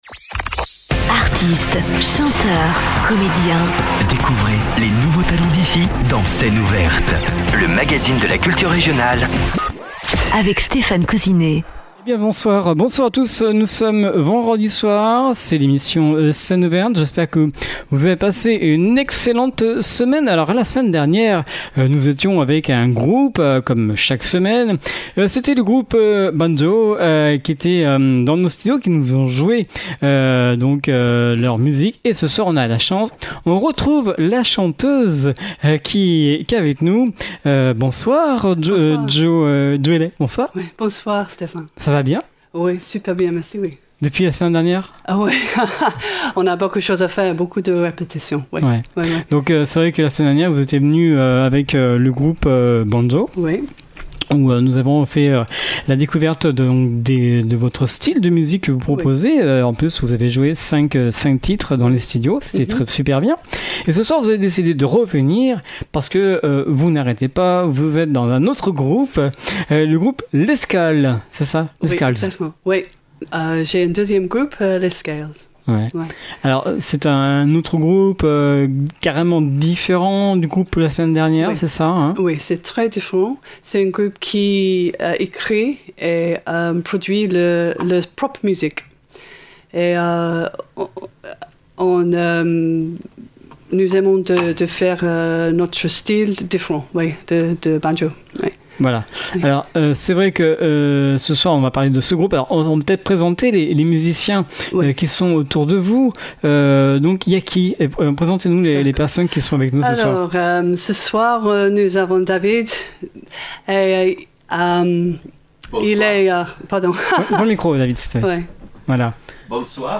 voix
guitare basse
guitare solo
guitare rythmique